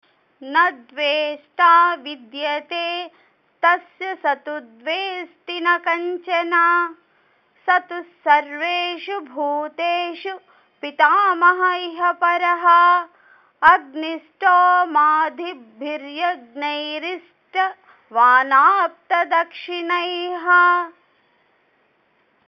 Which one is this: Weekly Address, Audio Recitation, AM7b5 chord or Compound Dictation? Audio Recitation